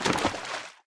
sounds / material / human / step / t_water2.ogg
t_water2.ogg